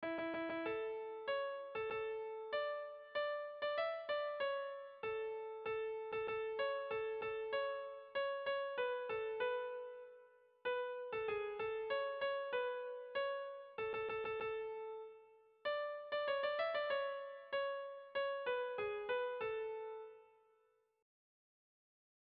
Kontakizunezkoa
Zortziko txikia (hg) / Lau puntuko txikia (ip)
ABDE